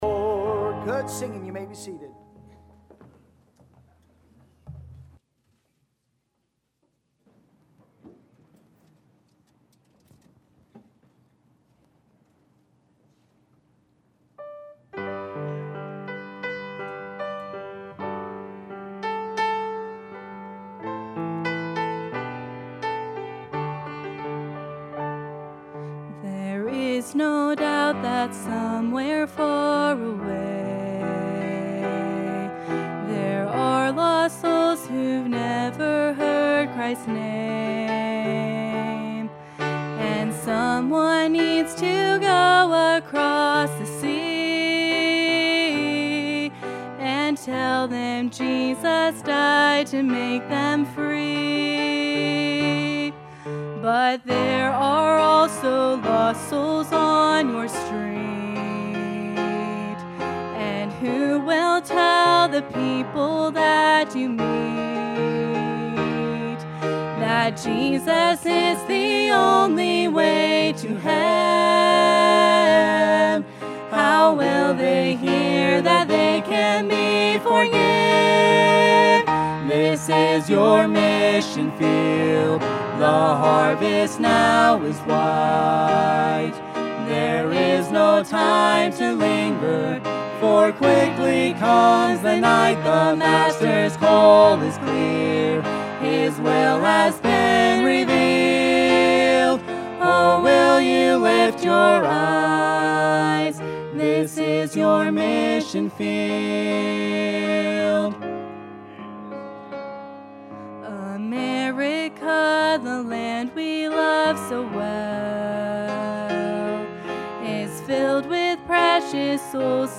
Sunday Evening Service